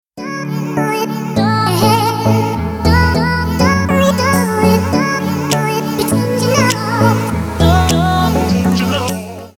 • Качество: 320, Stereo
dance
Electronic
EDM
club